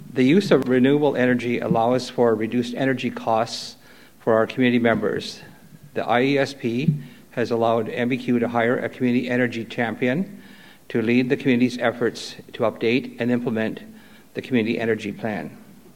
The Province of Ontario announced a major increase to the Indigenous Energy Support Program on Tuesday morning.